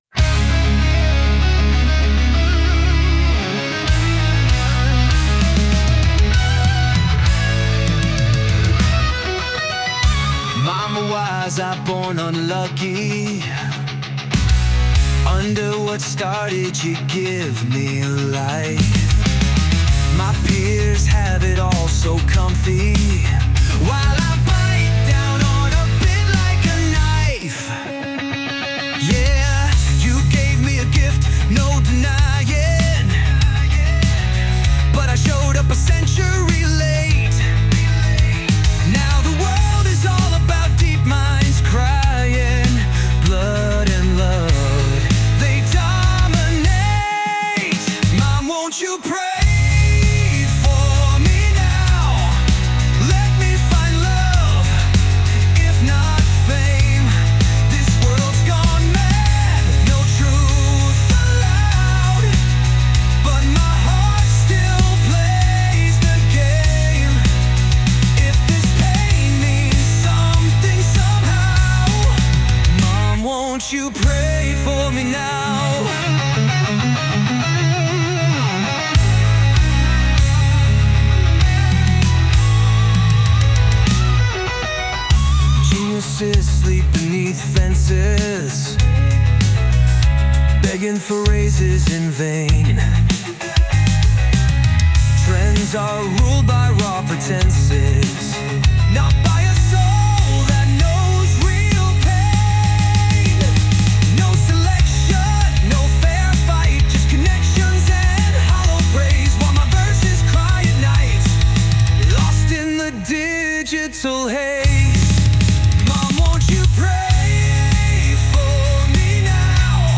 English, Metal, Punk, Trance | 22.03.2025 19:18